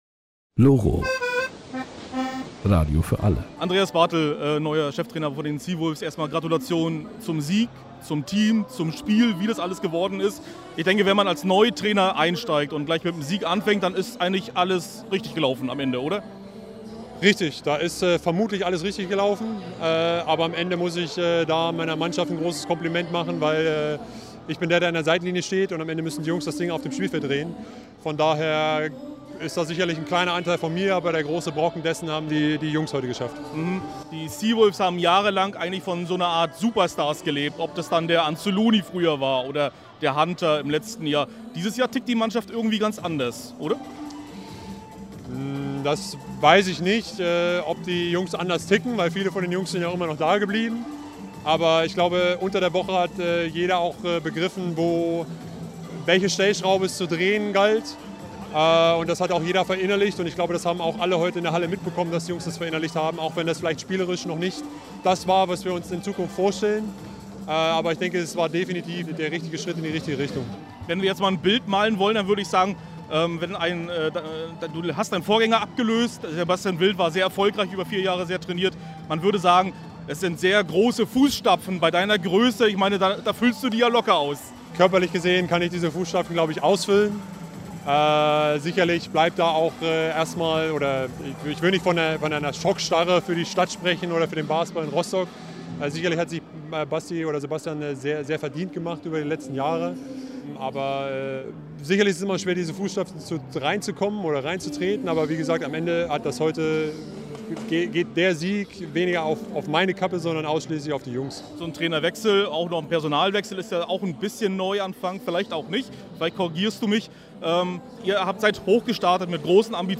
Im Interview